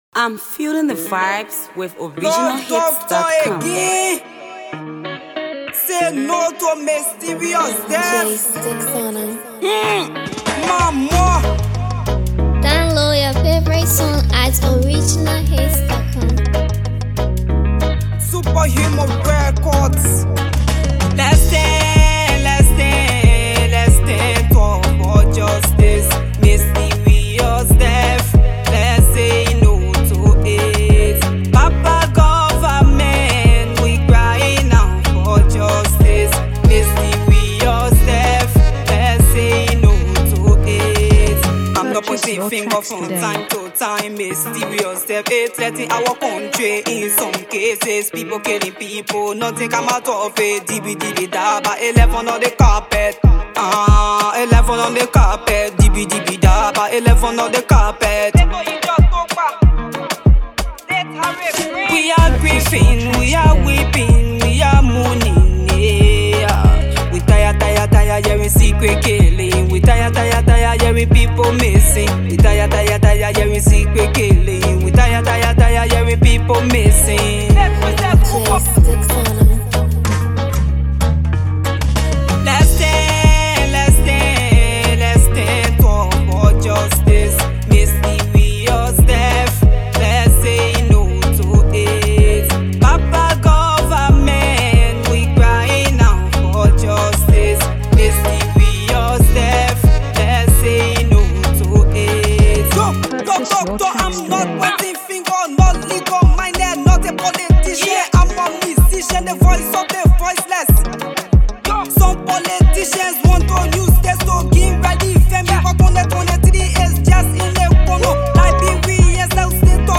Liberian Versatile Female Hipco Artist